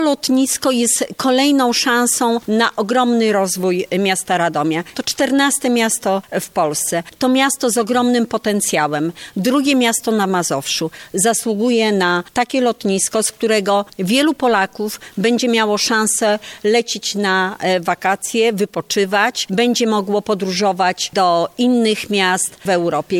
Jak ważne jest lotnisko dla przyszłości Radomia mówi poseł Prawa i Sprawiedliwości Anna Kwiecień: